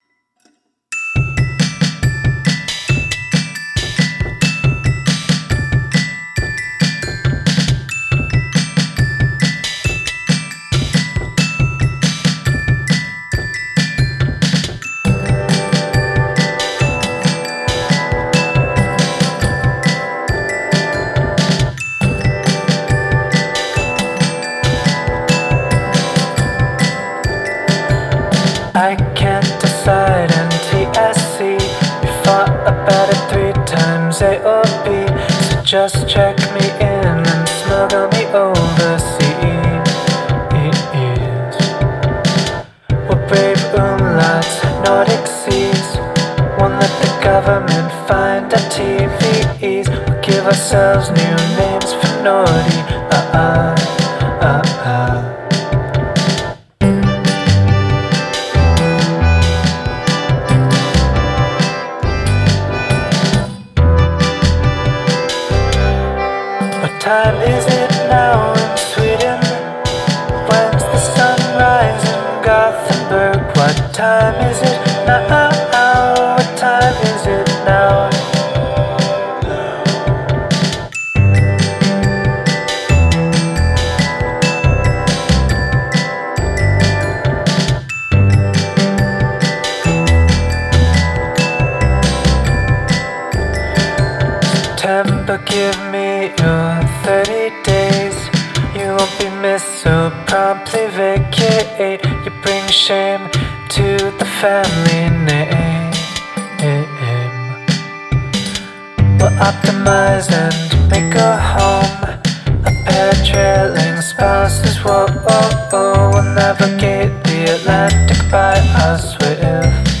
electro-pop
headed to Sweden and recorded a solo project